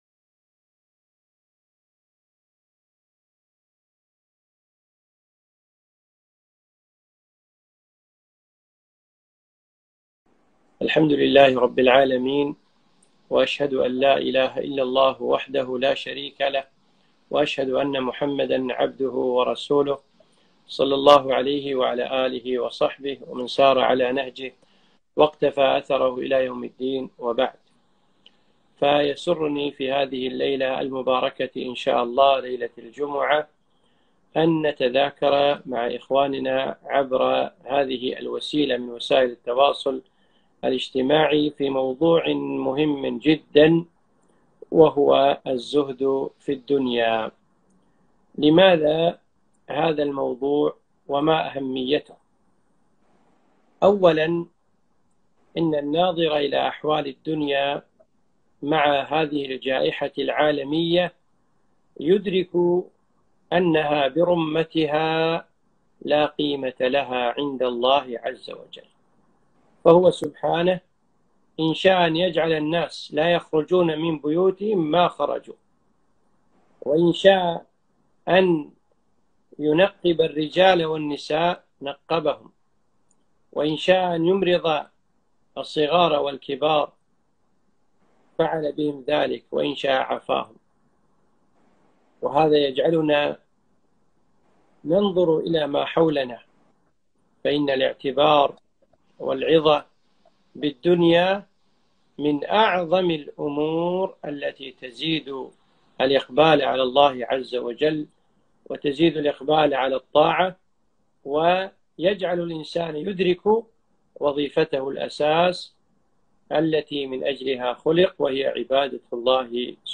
محاضرة - الزهد في الدنيا